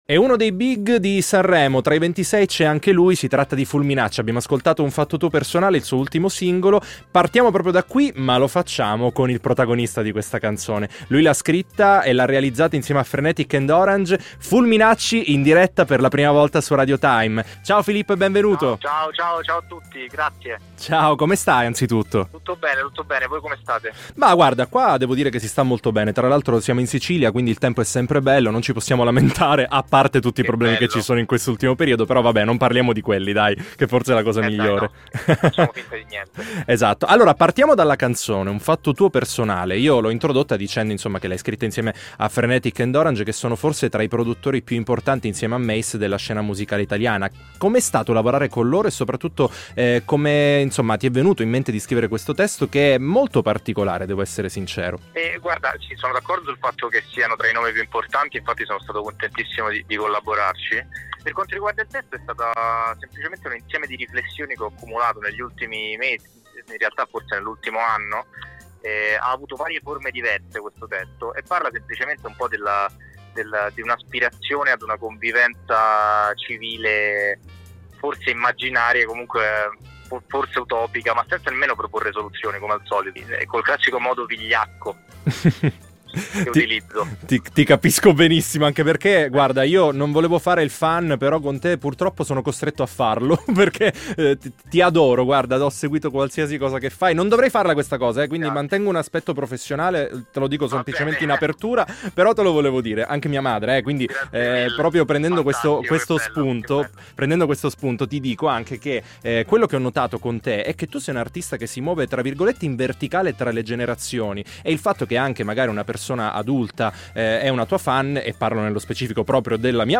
INTERVISTA-FULMINACCI-A-TIME-ITALIA-online-audio-converter.com_.mp3